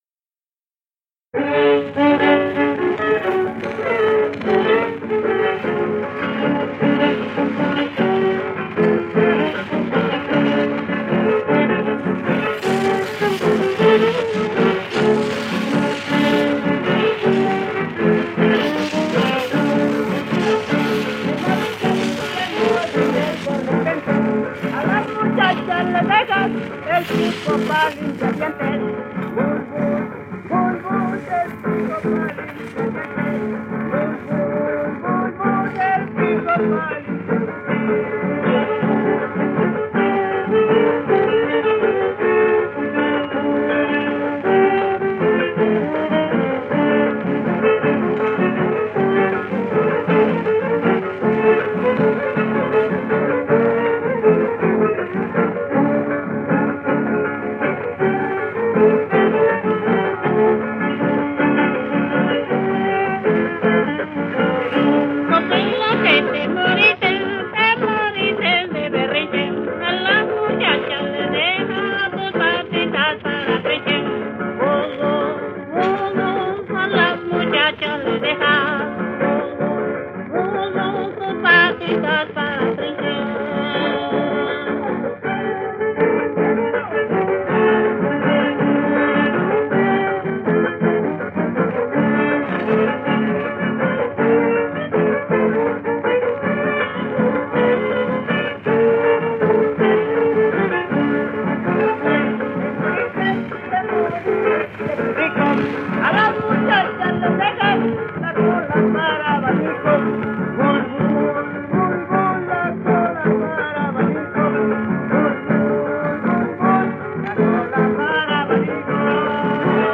Keywords: huapangos poblanos
violín y voz
voz y jarana
voz, guitarra sexta y guitarra quinta
trovador.